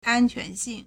安全性 ānquánxìng
an1quan2xing4.mp3